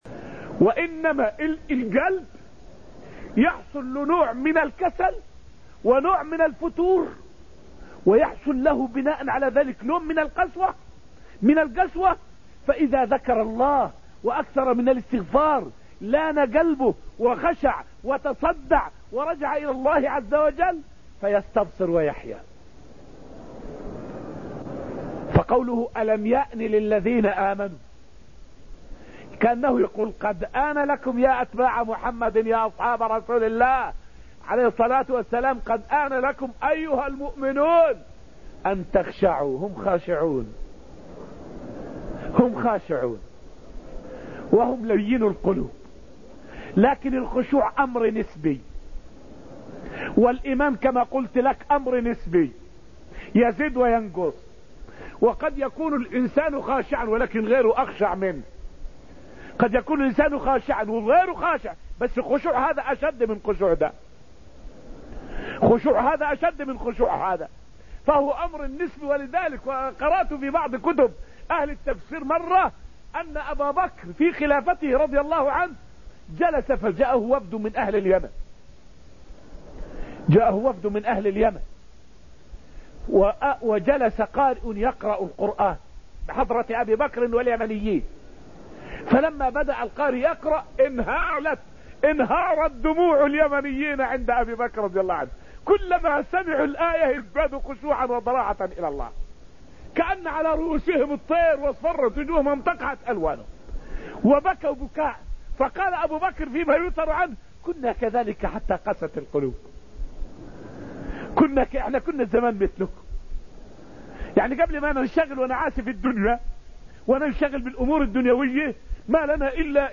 فائدة من الدرس العاشر من دروس تفسير سورة الأنفال والتي ألقيت في رحاب المسجد النبوي حول أثر الفتن على القلوب.